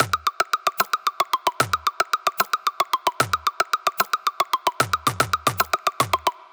Titan Perc Loop 34 – 150 BPM
Cymatics-Titan-Perc-Loop-34-150-BPM.wav